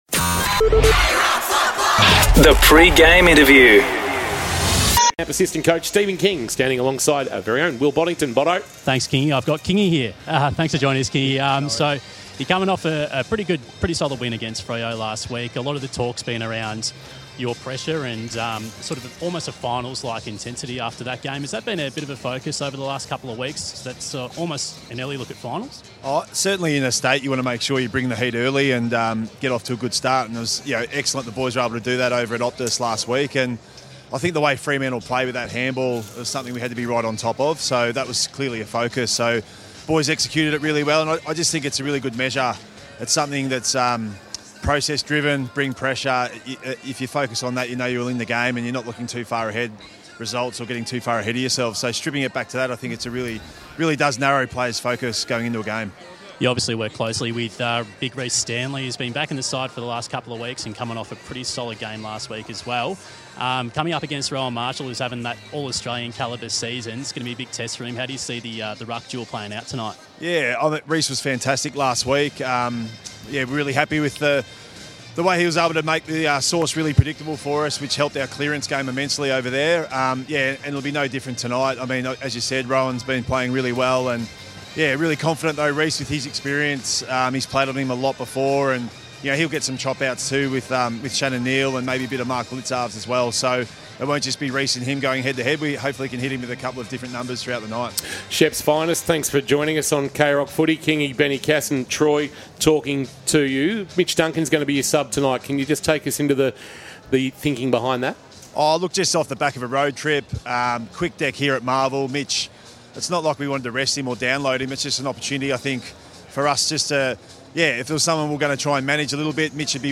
2024 - AFL - Round 23 - St Kilda vs. Geelong - Pre-match interview